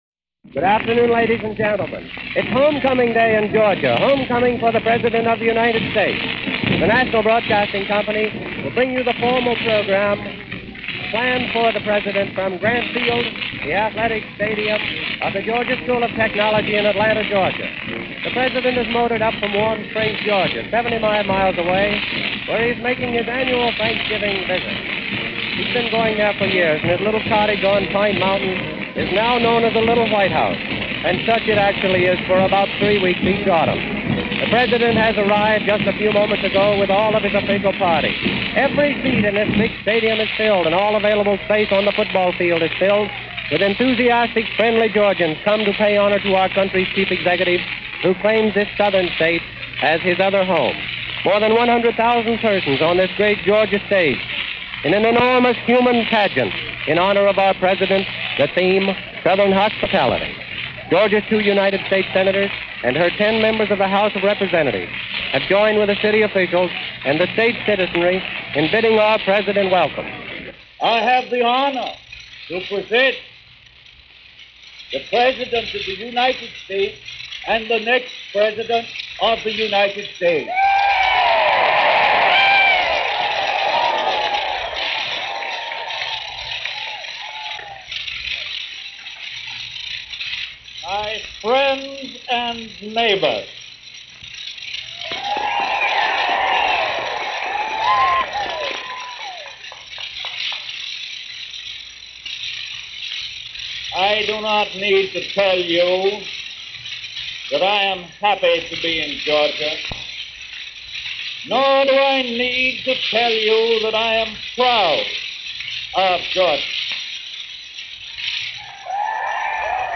FDR Talks about Taxes and the Budget - November 29, 1935 - President Franklin D. Roosevelt delivers an address about Taxes and the Budget.
Note: The sound quality on this post isn’t all that good – lots of noise and levels jumping around. So it may take some effort to understand the entire speech.